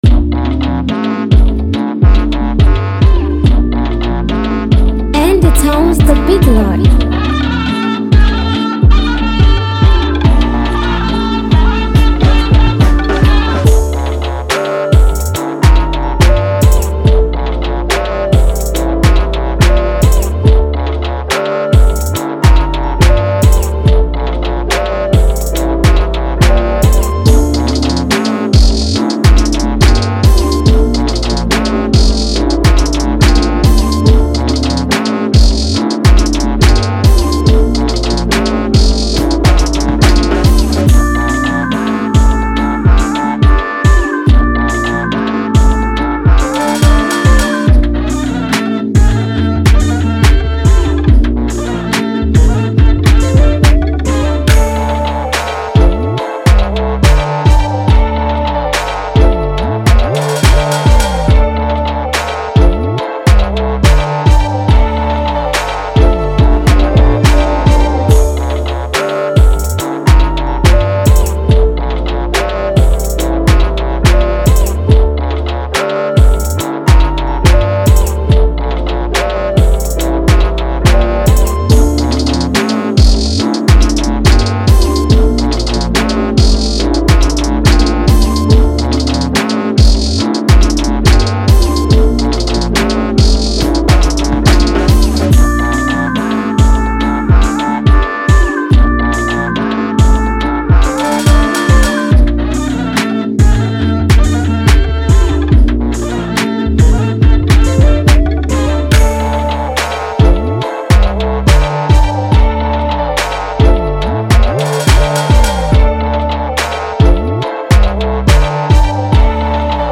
Freebeats